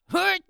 CK普通4.wav 0:00.00 0:00.51 CK普通4.wav WAV · 44 KB · 單聲道 (1ch) 下载文件 本站所有音效均采用 CC0 授权 ，可免费用于商业与个人项目，无需署名。
人声采集素材/男2刺客型/CK普通4.wav